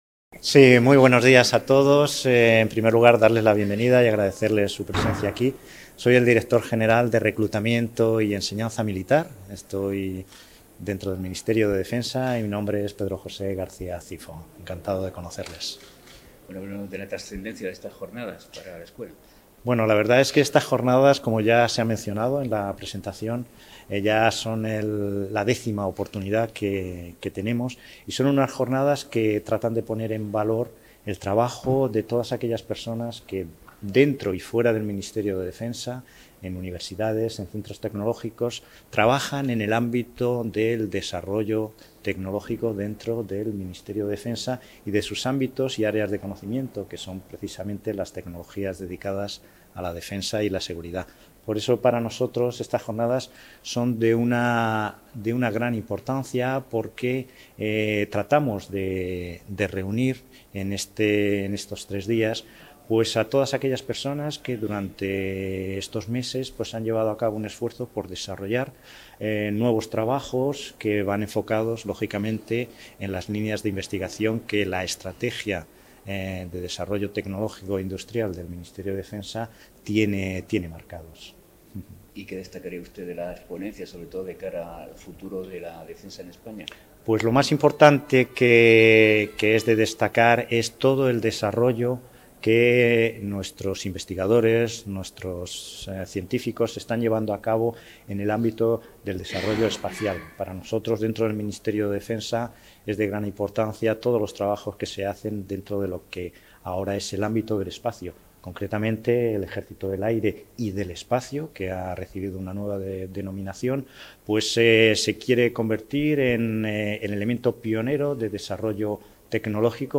Noelia Arroyo inaugura el X Congreso Nacional de I+D en Defensa, que se celebra en Cartagena del 14 al 16 de noviembre